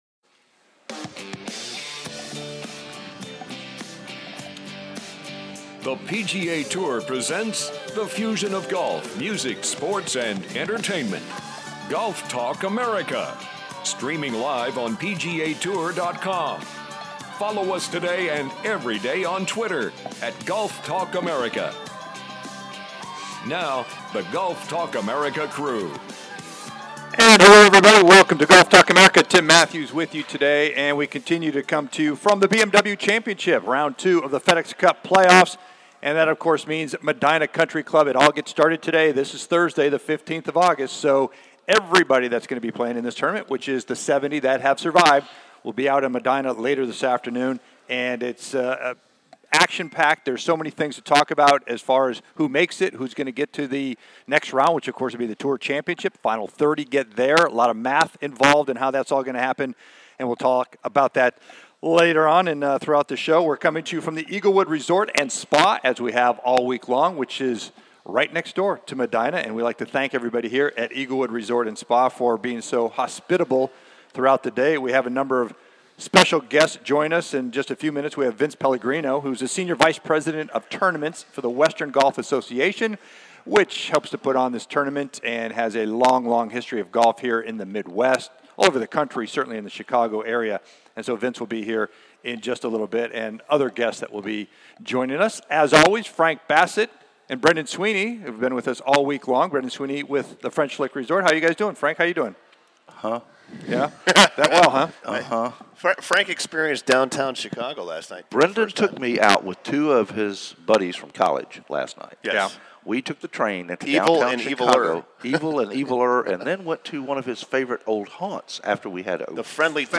"LIVE" At The BMW Championship, The Eaglewood Resort & Spa